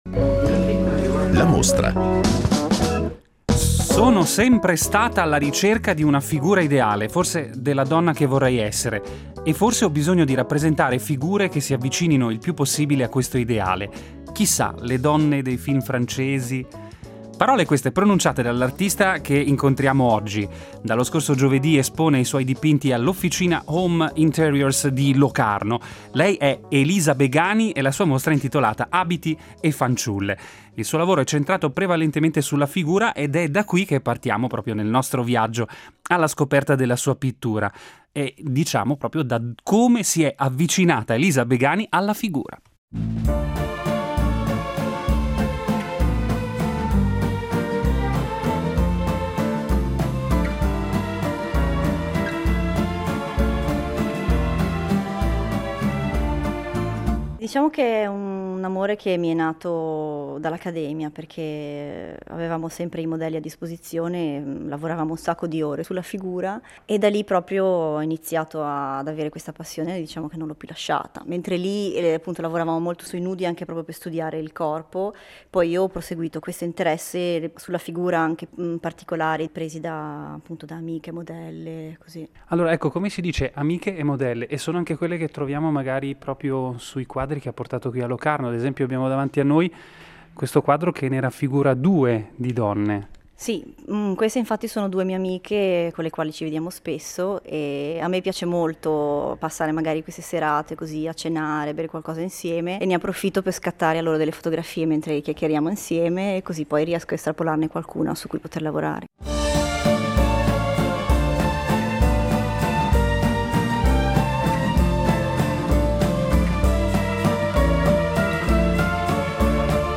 mostra “Abiti e fanciulle”, Locarno (CH), gennaio 2009
01-Intervista.mp3